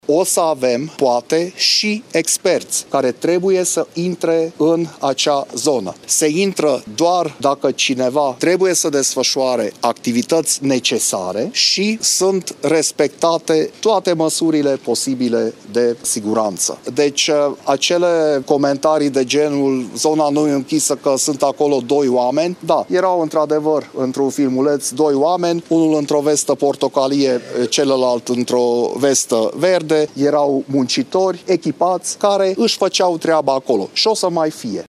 Despre filmările în care apar oameni prezenți în craterelor apărute la Praid, prefectul județului Harghita spune că doar muncitorii și experții au voie în acel perimetru: